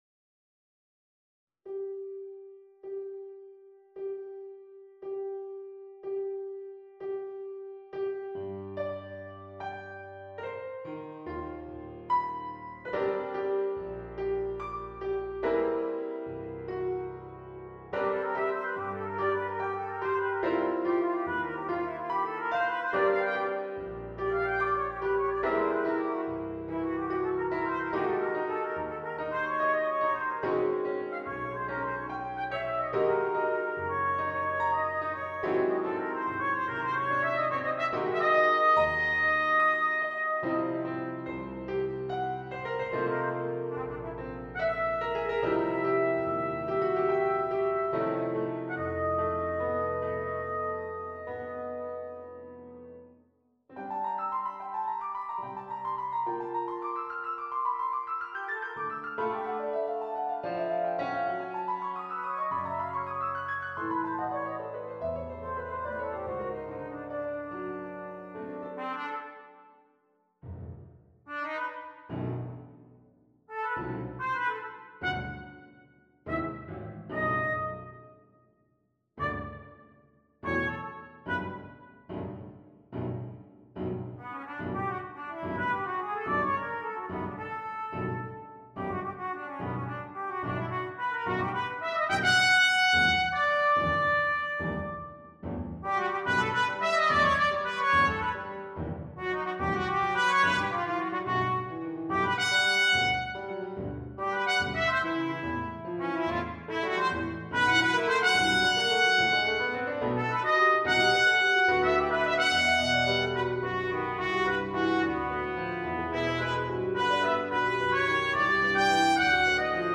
Commissioning Melody Eötvös and performing "Light Form" for trumpet in C and piano